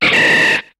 Cri de Chapignon dans Pokémon HOME.